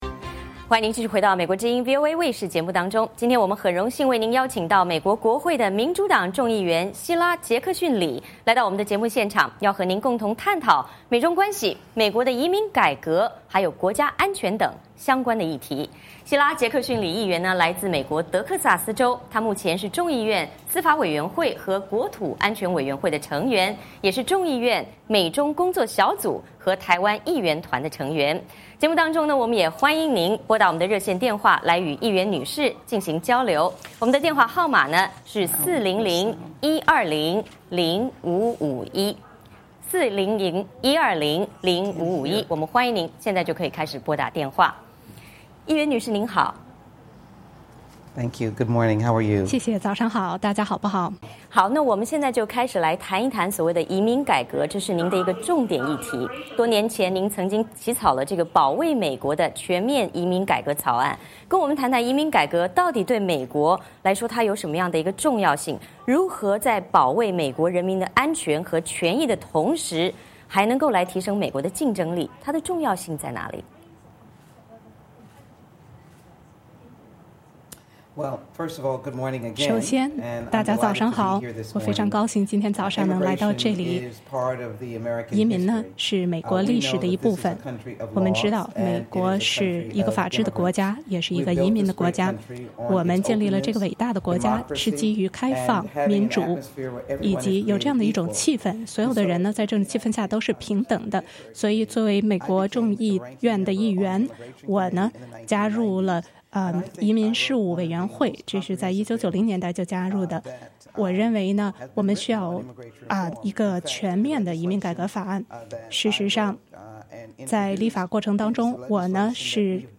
VOA卫视专访美国民主党众议员希拉·杰克逊·李